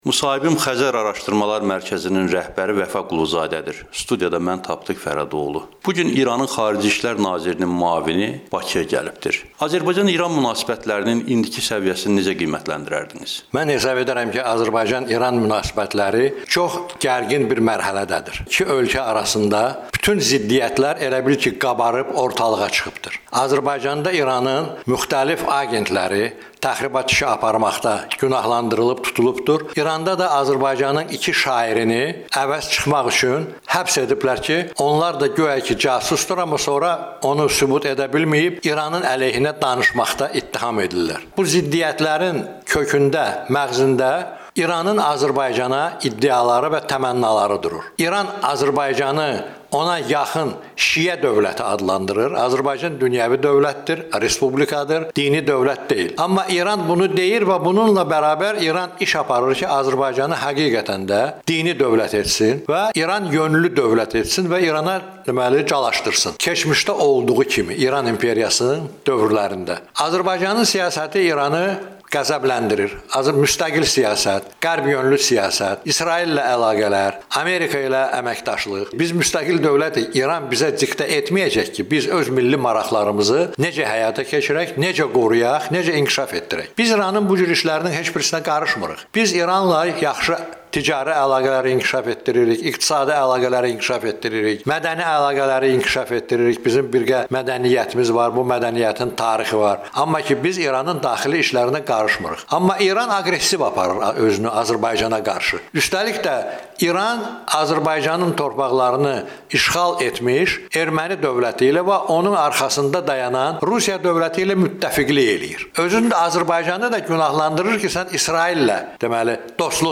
Vəfa Quluzadə ilə müsahibə